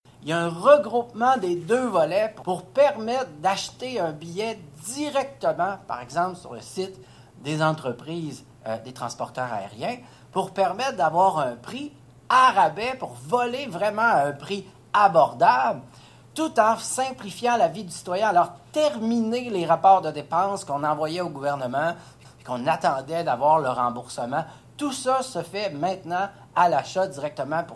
Fini les demandes de remboursement, s’est exclamé en point de presse le député Montigny